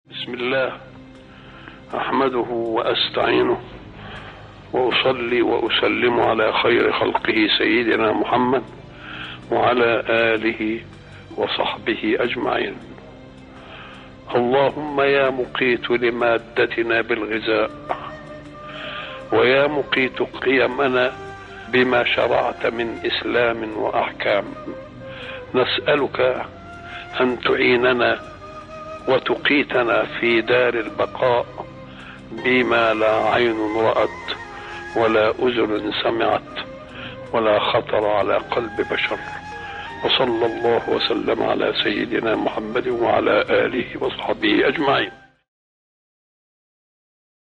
دعاء جامع يبدأ بالثناء على الله والصلاة على رسوله، ويحتوي على التضرع والطلب من الله الثبات على الدين والطاعة، والدعاء بالتقوى والنجاة في دار البقاء.